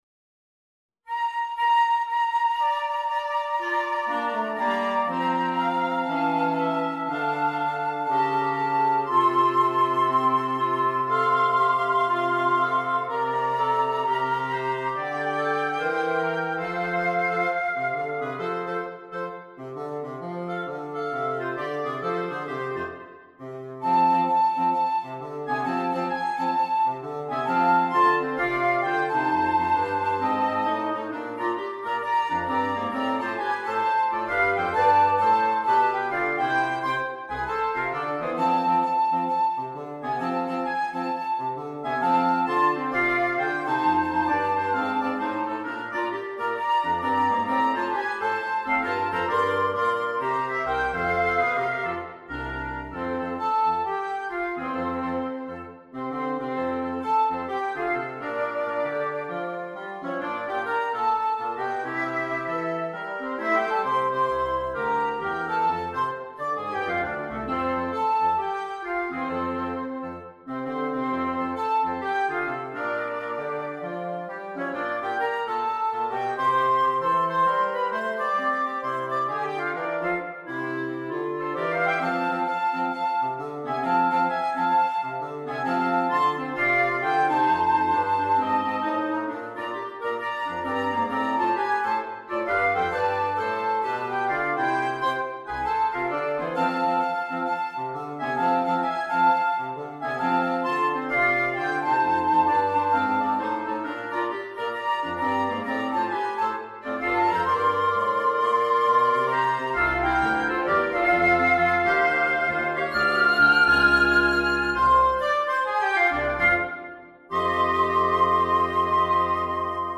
Christmas